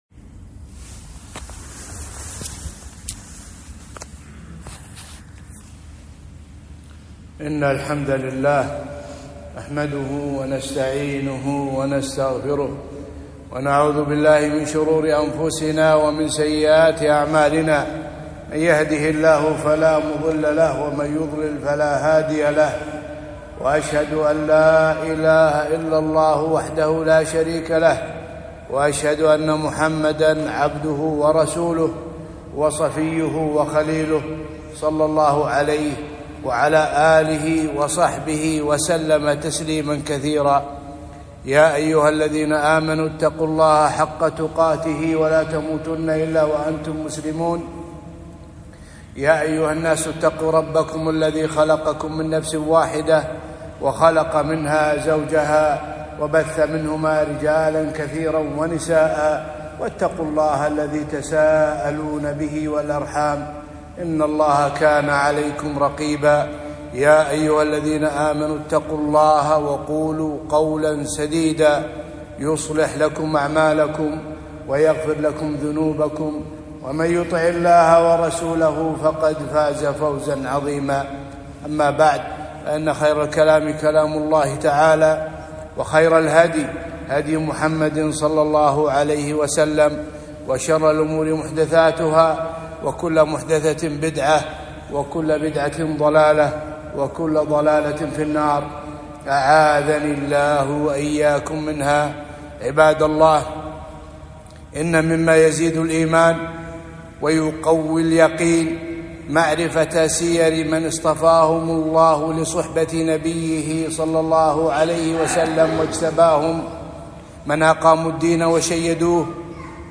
خطبة - الخليفة الراشد عثمان بن عفان رضي الله عنه - دروس الكويت